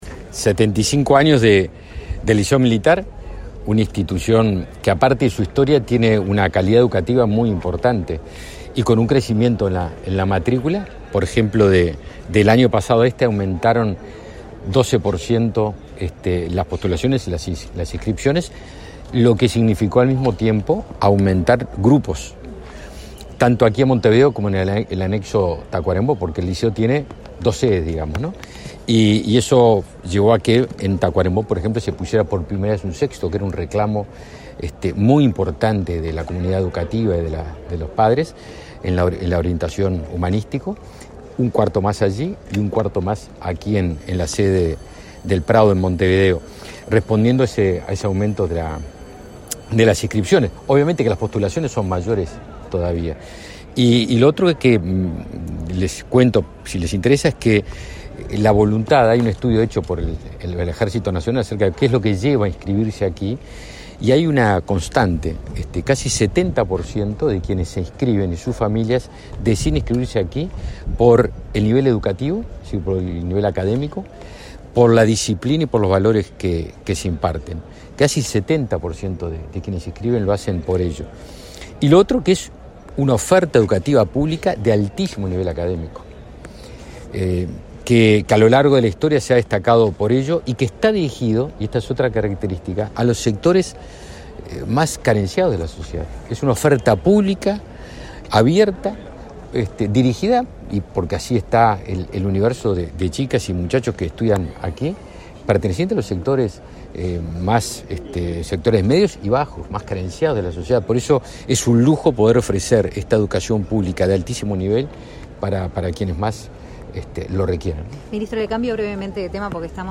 Declaraciones a la prensa del ministro Javier García
Este martes 15, el ministro de Defensa, Javier García, participó en el acto por los 75 años del Liceo Militar y, luego, dialogó con la prensa.